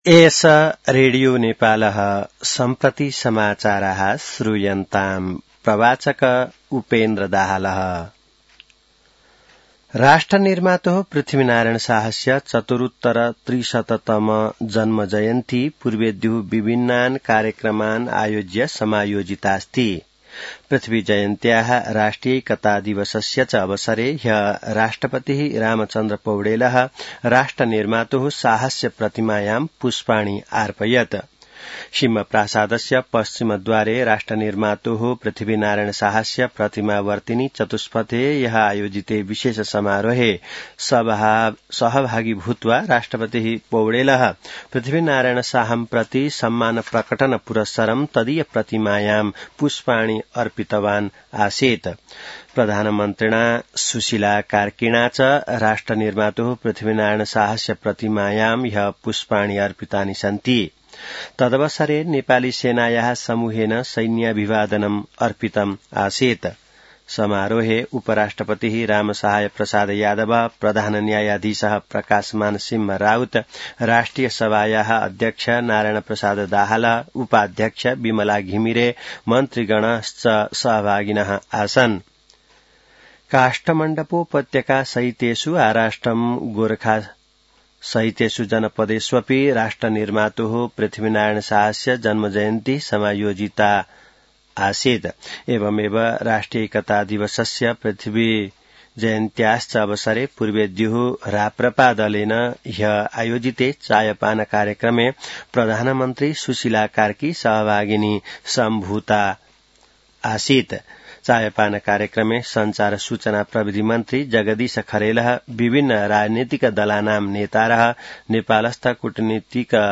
संस्कृत समाचार : २८ पुष , २०८२